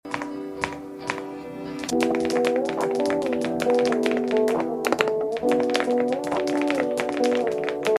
Green keyboard look like is sound effects free download